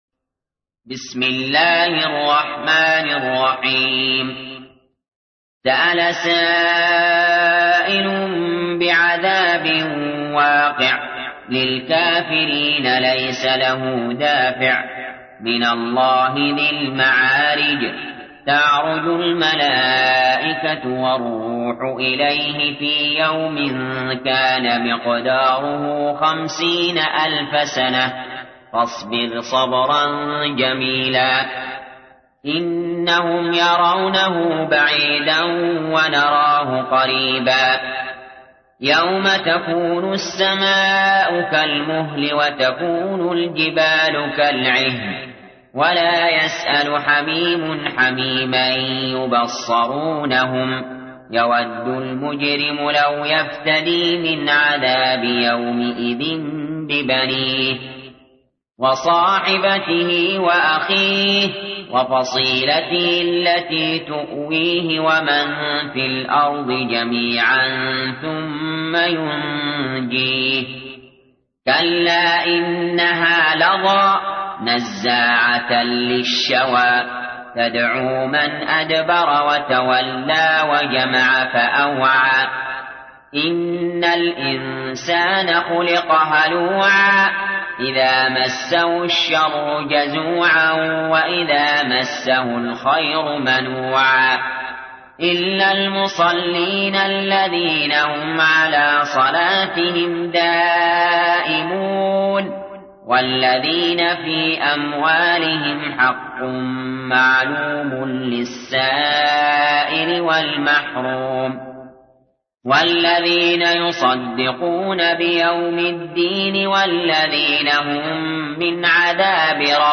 تحميل : 70. سورة المعارج / القارئ علي جابر / القرآن الكريم / موقع يا حسين